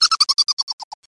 MouseLaugh3.mp3